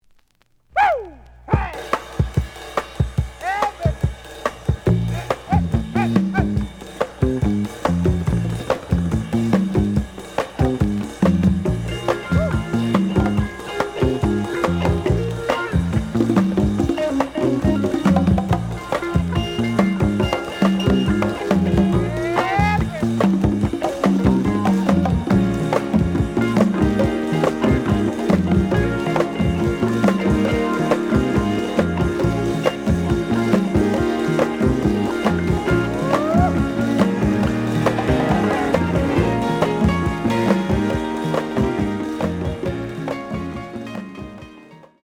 The audio sample is recorded from the actual item.
●Genre: Jazz Funk / Soul Jazz
Slight edge warp.